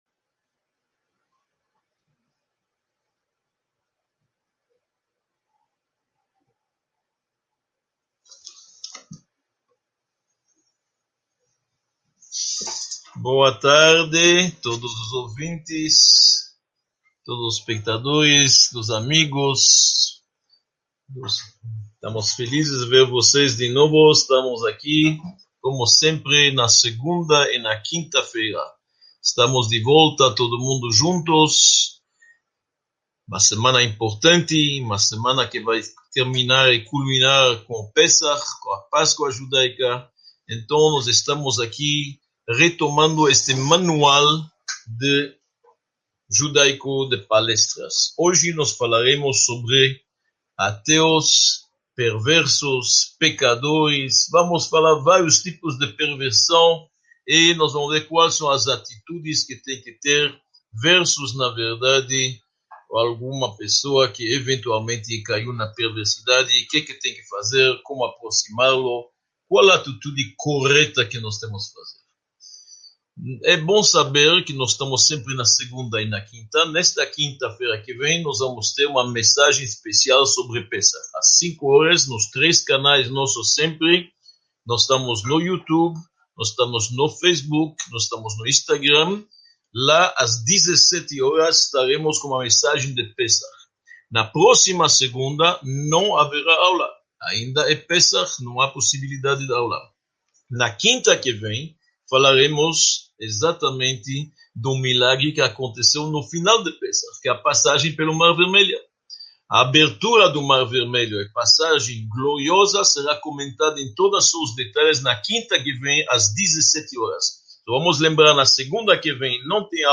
25 – Perversos e pecadores: como tratá-los | Módulo I – Aula 25 | Manual Judaico